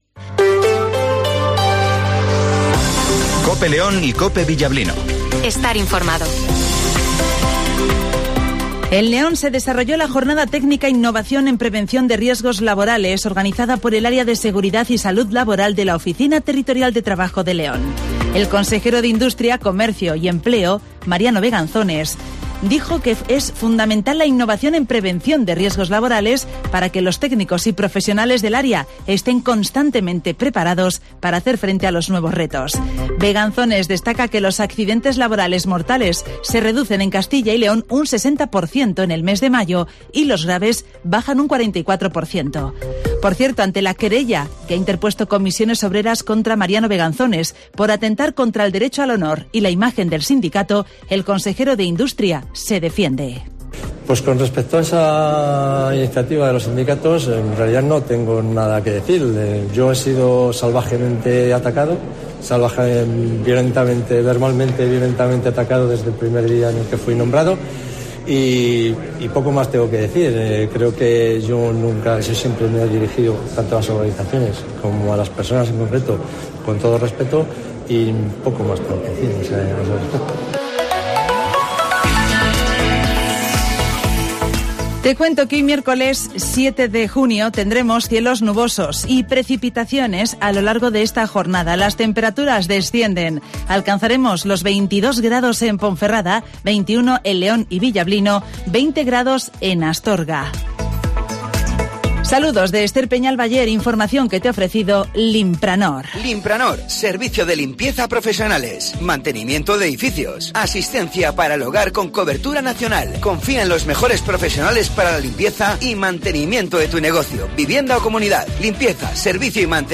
Redacción digital Madrid - Publicado el 07 jun 2023, 08:20 - Actualizado 07 jun 2023, 14:20 1 min lectura Descargar Facebook Twitter Whatsapp Telegram Enviar por email Copiar enlace - Informativo Matinal 08:20 h